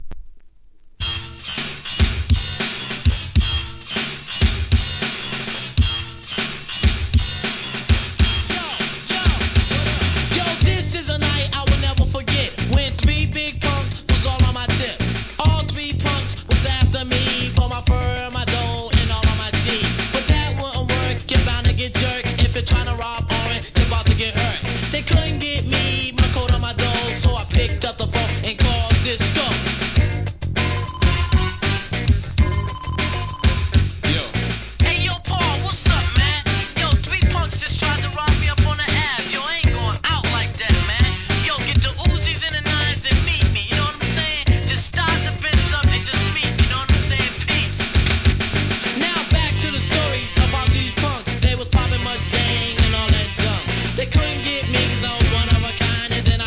RARE INDIE LABEL RAP!!